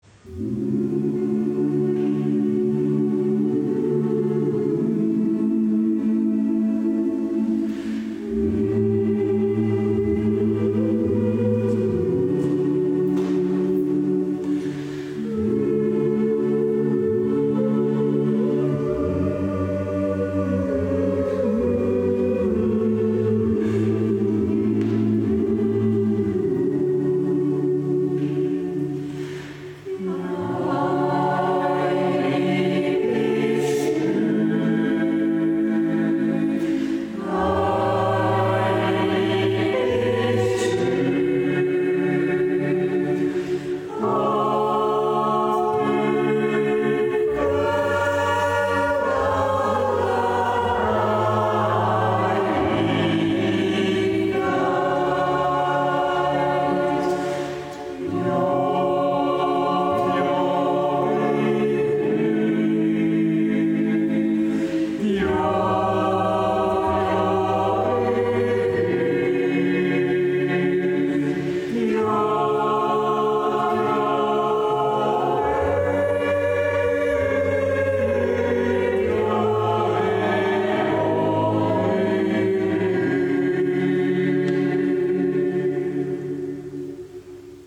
Andachtsjodler aus Österreich